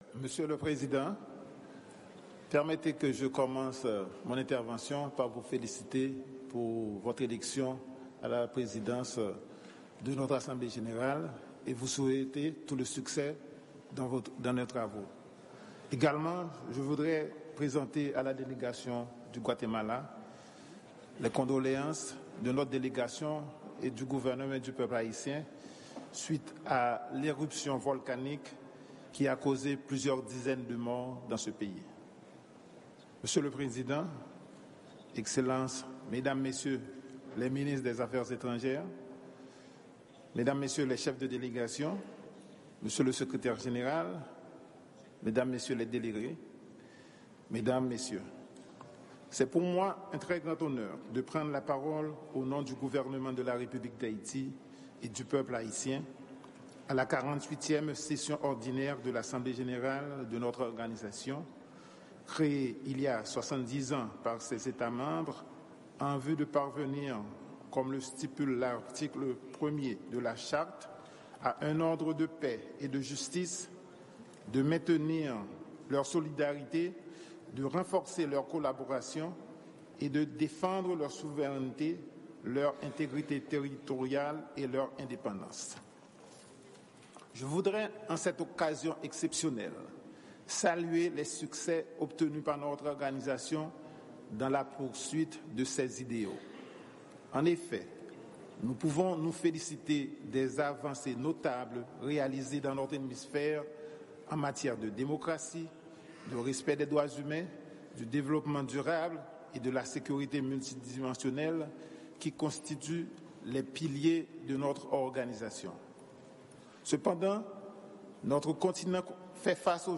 Diskou Minsi Afè Etranjè Ayisyen an, Antonio Rodrigue, nan okazyon 48èm sesyon òdinè Asanble Jeneral l'OEA a.